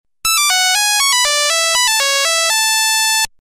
Звук мелодии